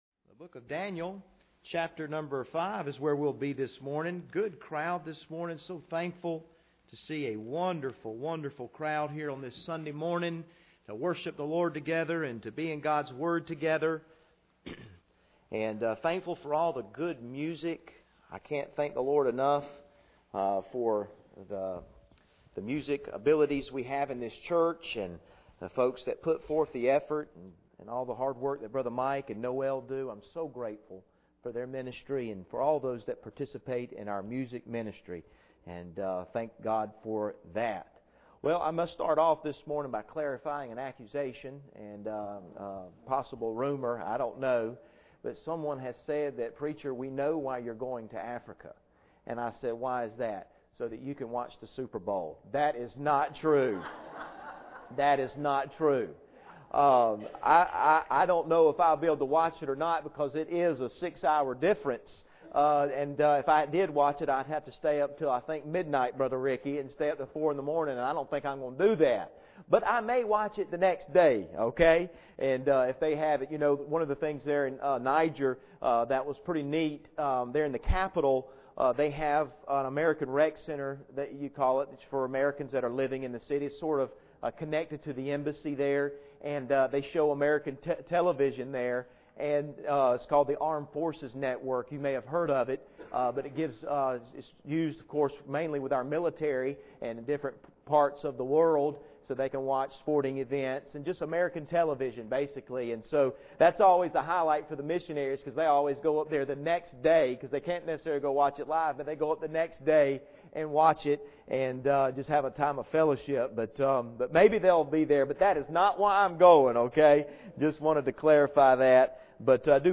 Passage: Daniel 5:13-30 Service Type: Sunday Morning